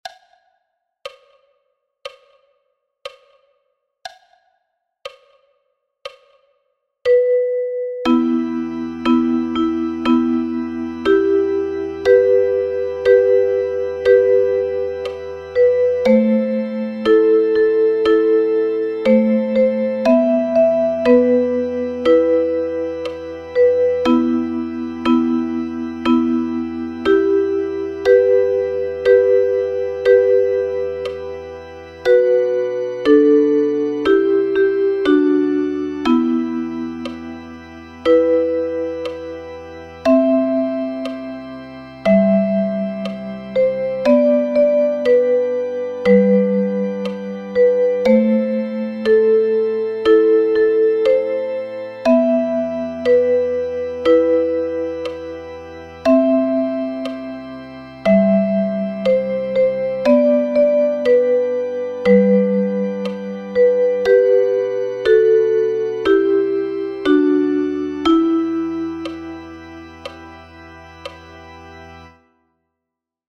notated as duets for Soprano Recorder and Alto Recorder.